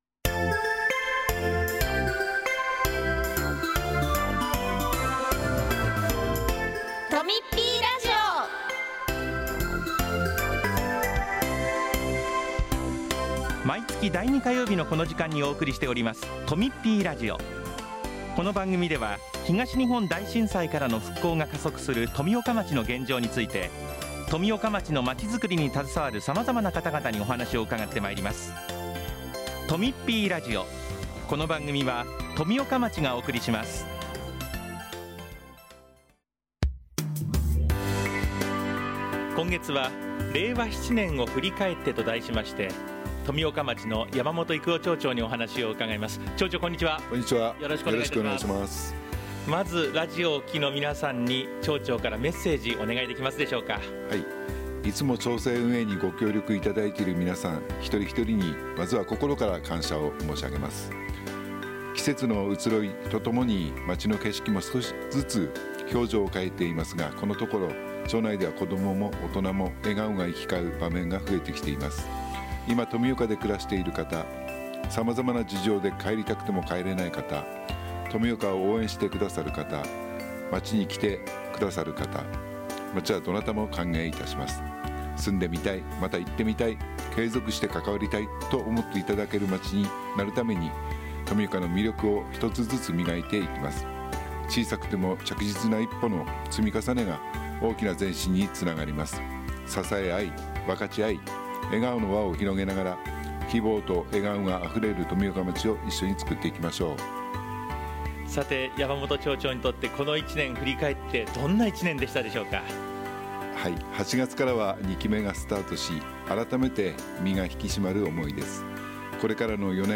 今回は、山本 育男 町長が「令和7年を振り返って」というテーマお話ししています。